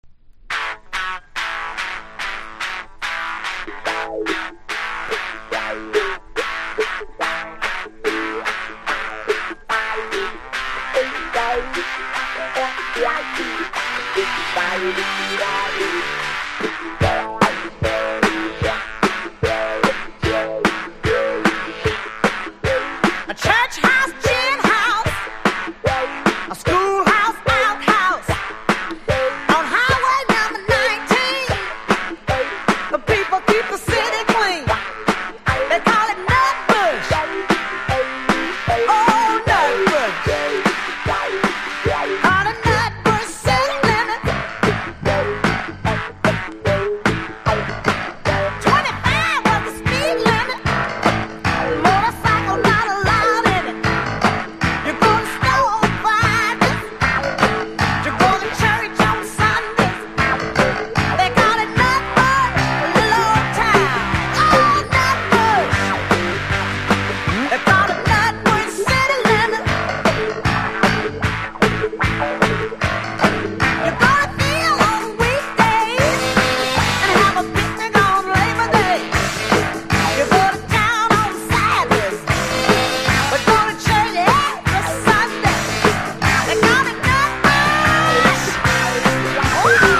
70’s ROCK